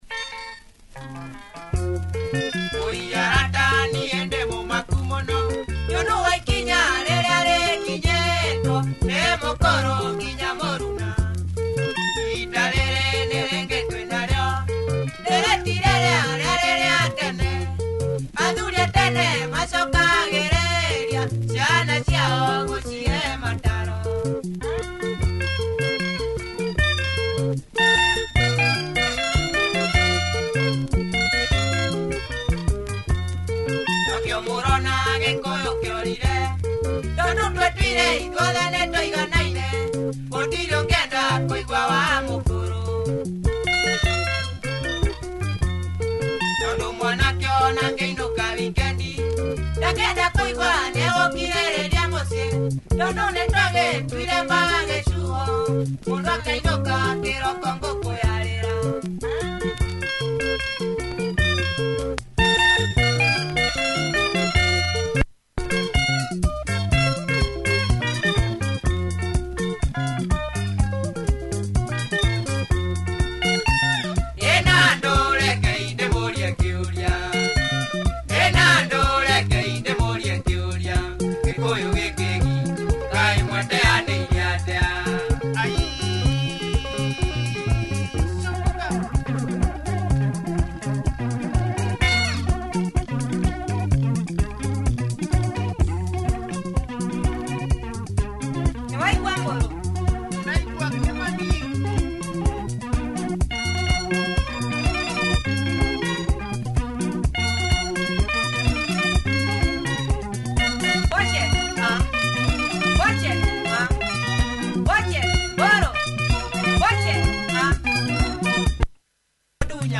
Good beat and tempo on this one, weird and blurred guitar.
Don’t know this Kikuyu group, check the audio!